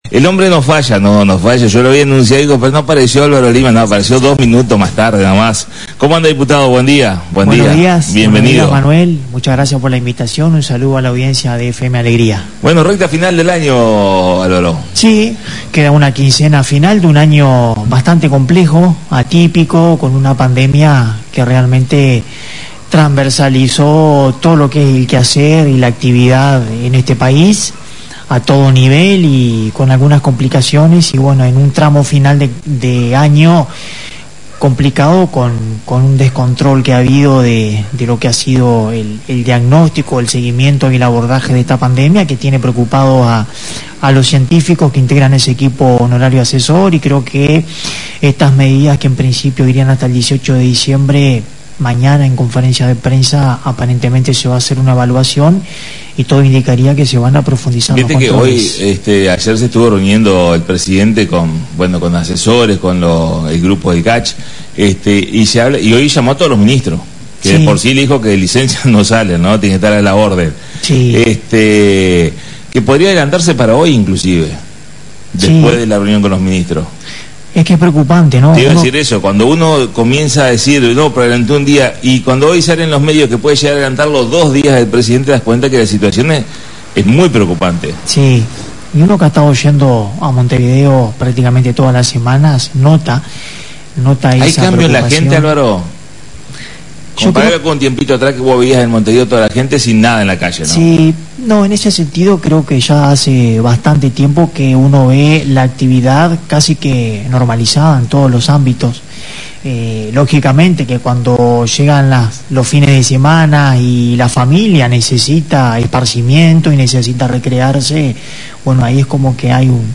La visita a la Radio del Diputado por el Frente Amplio Dr. Álvaro Lima.